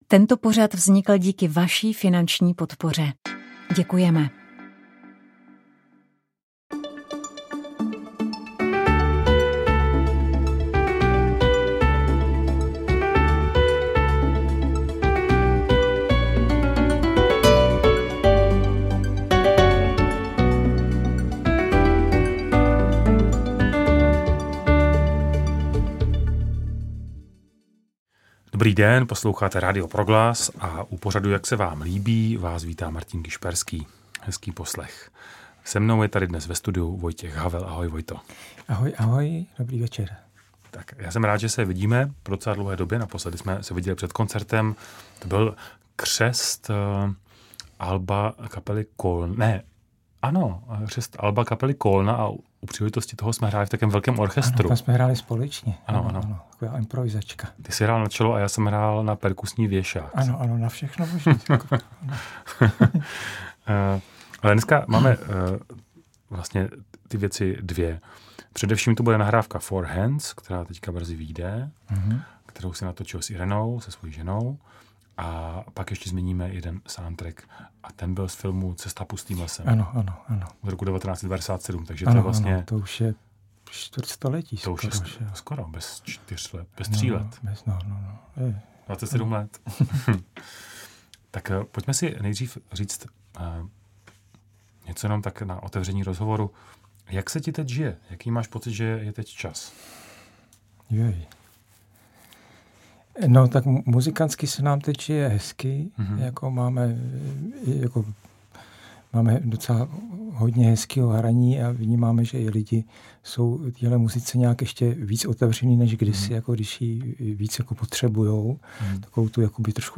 Rozhovor s Letní kapelou v lednu